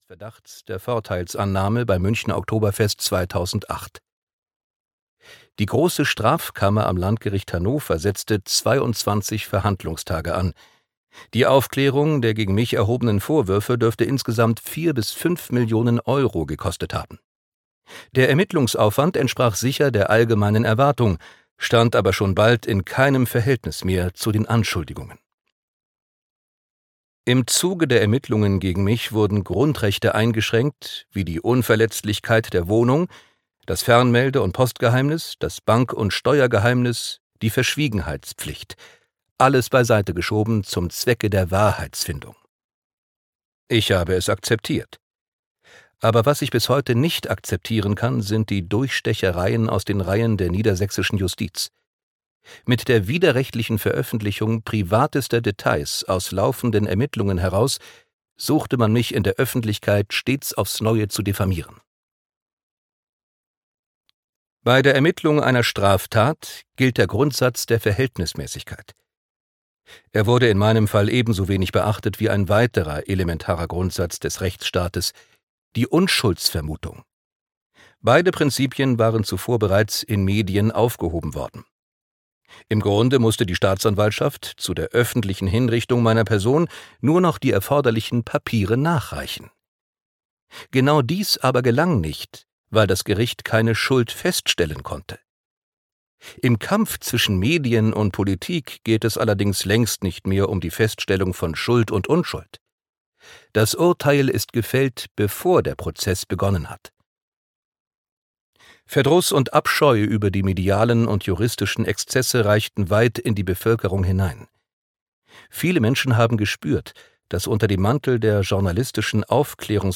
Ganz oben Ganz unten - Christian Wulff - Hörbuch